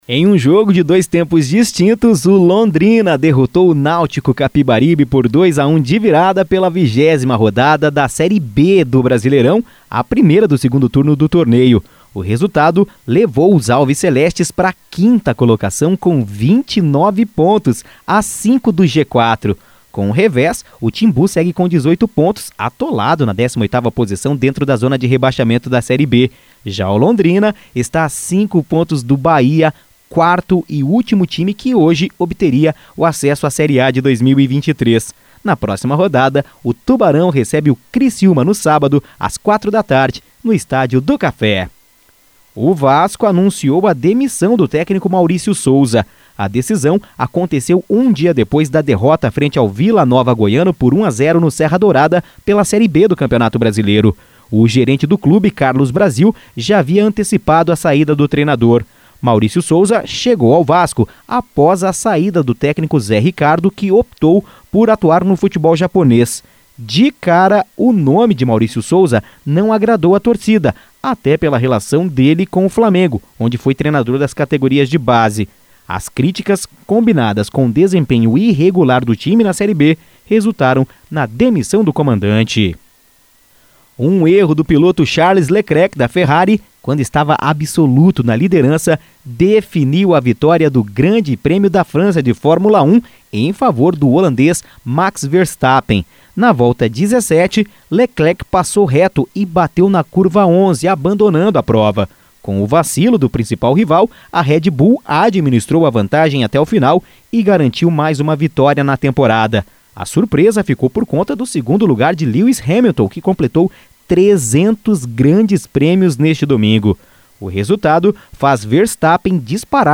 Giro Esportivo (SEM TRILHA)